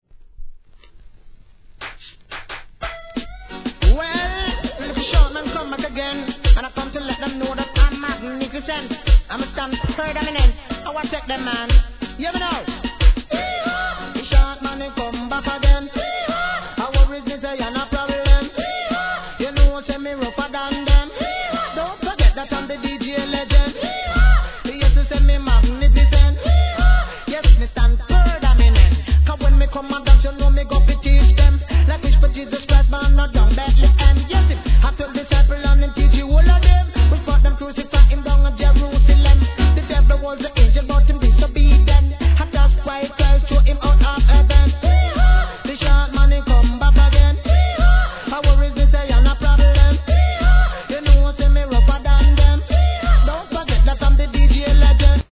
REGGAE
「ヒーハ〜」が妙にマッチするチープなRHYTHM最高です!